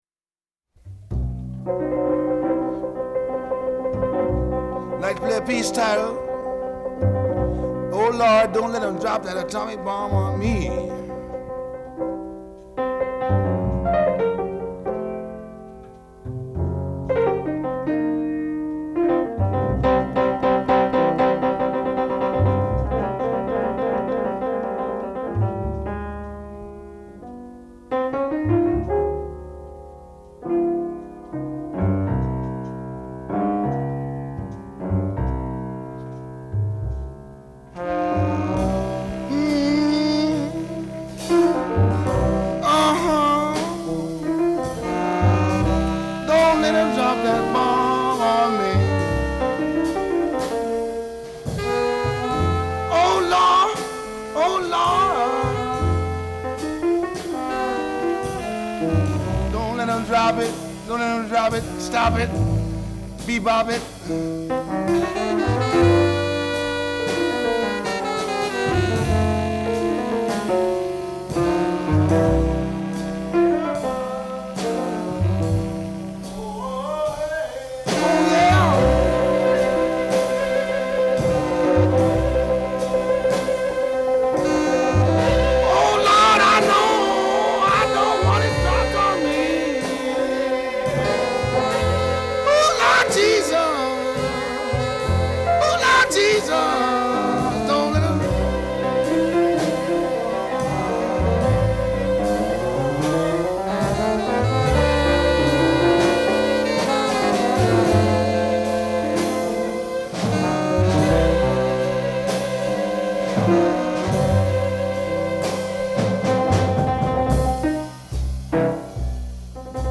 piano
a ballad with wonderful piano flourishes